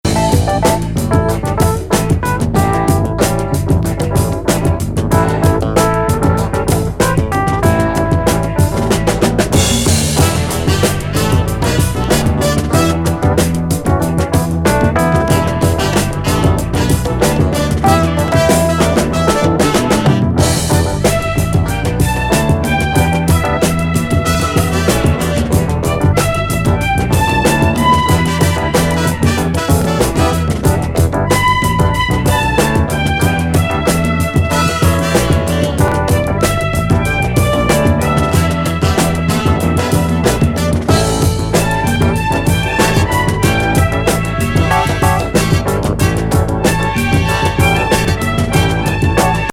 セクシー・ボイス+雨SE入りスウィート・ソウル。